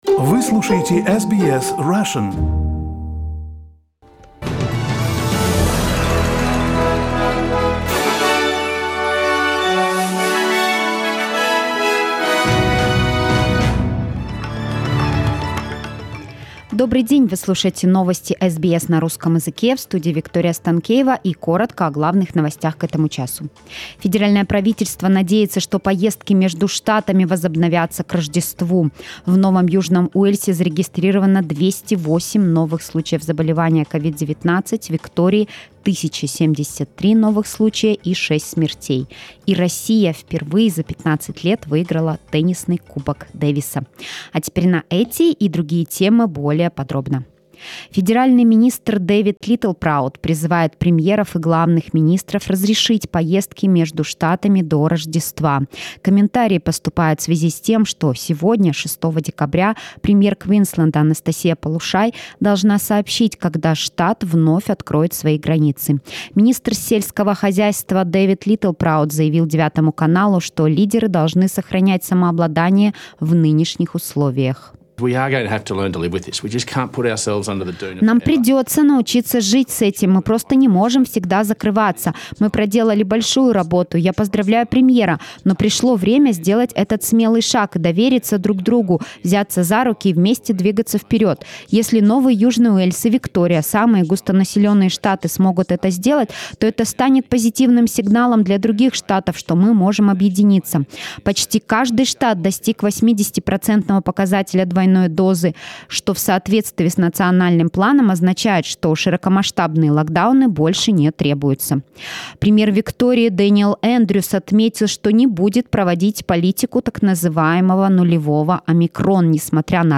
SBS news in Russian - 6.12